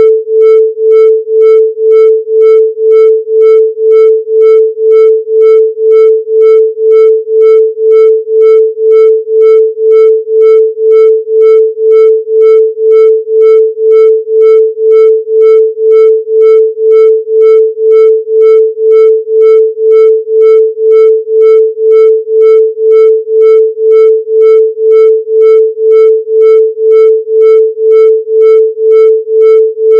An effective method for doing so is to get two frequencies to ‘beat’* together at the brain wave rhythm rate.
The files are based on pure Sine waves around 440 Hz (A)  and are therefore a little hard on the ear, however, they accurately represent the frequencies of interest.
On 440 Hz carrier wave
Delta2.wav